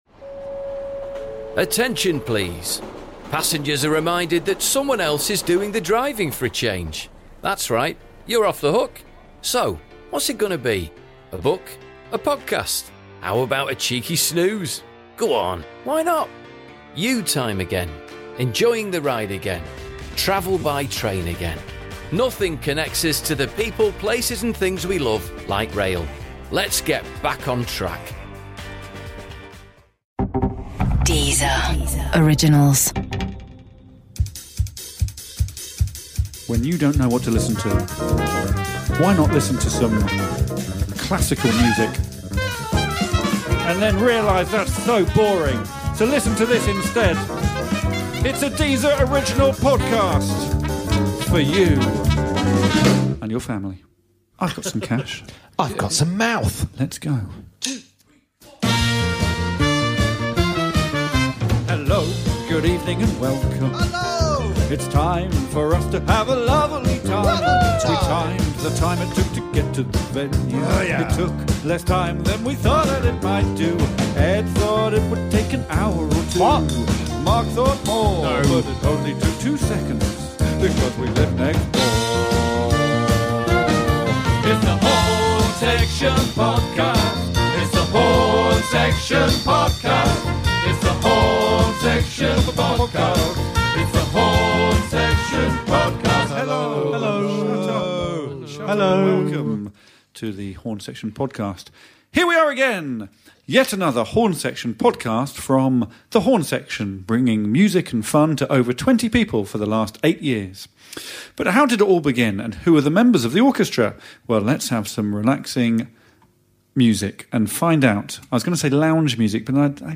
Welcome to The Horne Section Podcast, your new weekly dose of musical nonsense and anarchic chat with Alex Horne and his band! This week we're joined in the studio by comedian, writer and podcaster Adam Buxton. Contains alphabetical storytelling, distinctly average David Bowie impressions and a fascinating insight into Adam's morning routine.